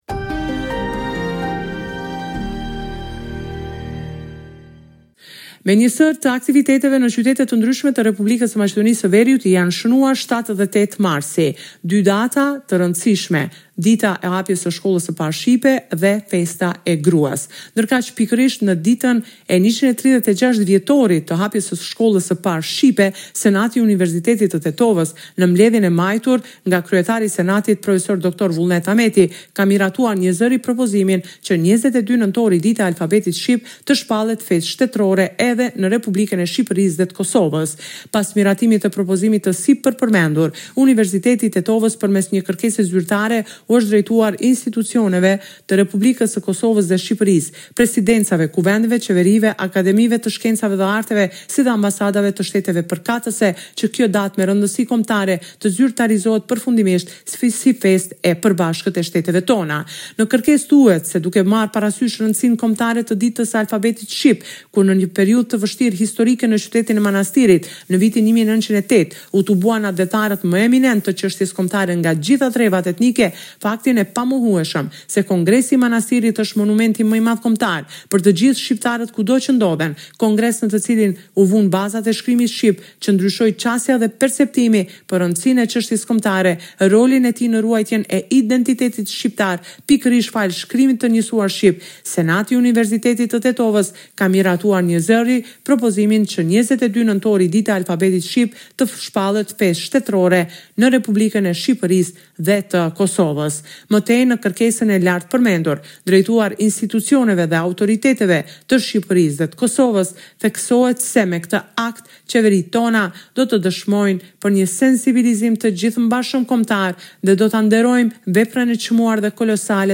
Raporti me të rejat më të fundit nga Maqedonia e Veriut.